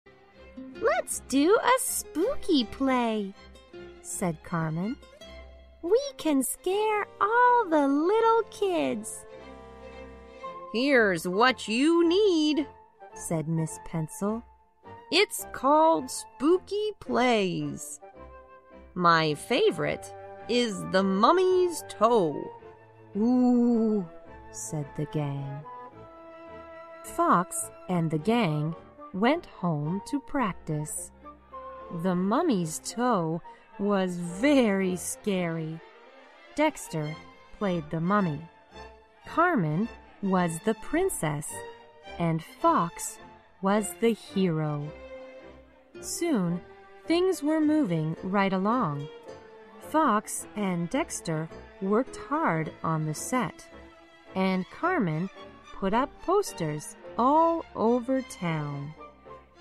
在线英语听力室小狐外传 第71期:鬼故事的听力文件下载,《小狐外传》是双语有声读物下面的子栏目，非常适合英语学习爱好者进行细心品读。故事内容讲述了一个小男生在学校、家庭里的各种角色转换以及生活中的趣事。